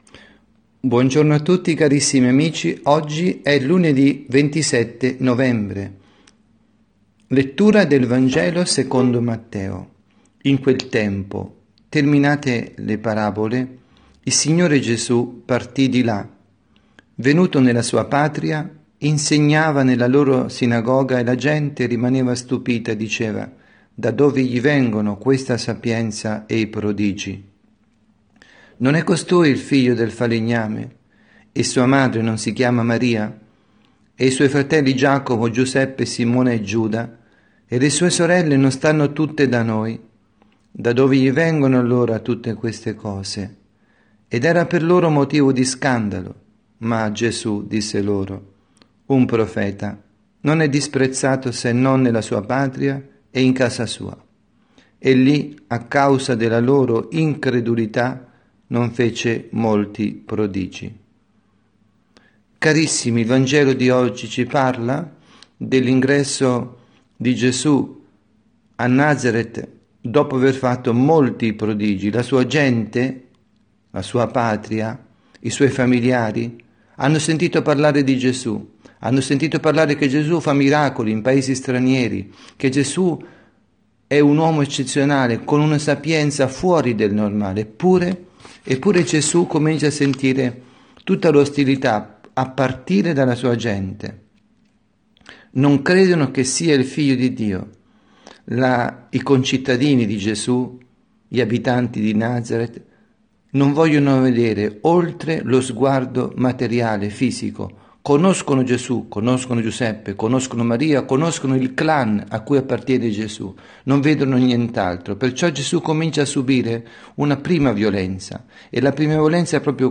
avvisi, Omelie
dalla Parrocchia S. Rita –  Milano